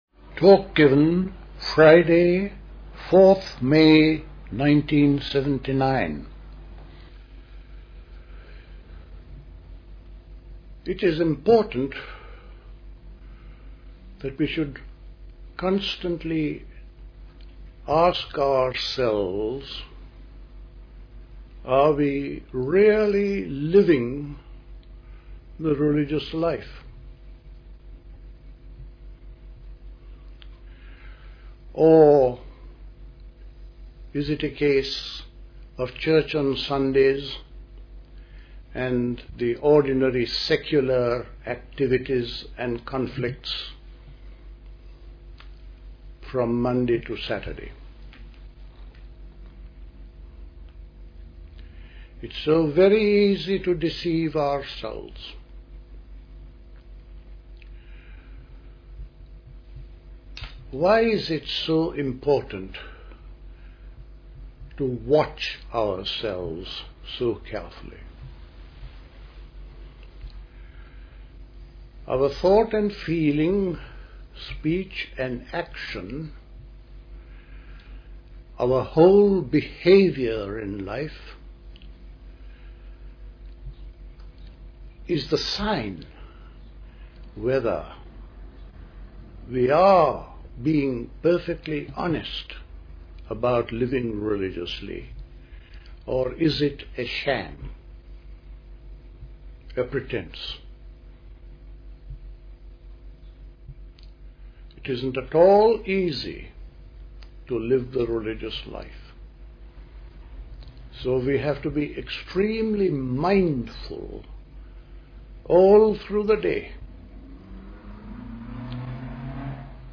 Today's Talk: Birth and Death.